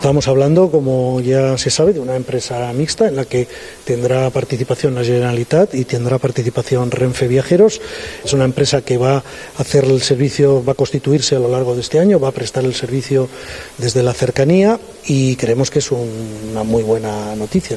Així descrivia l’entesa el secretari d’Estat de Transports, José Antonio Santano.